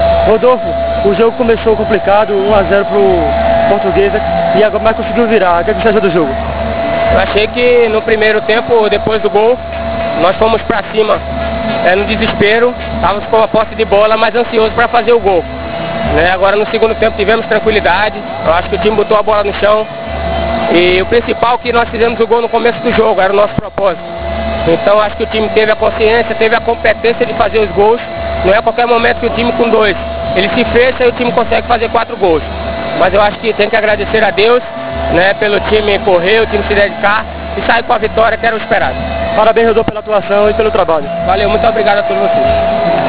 ENTREVISTA *Em Audio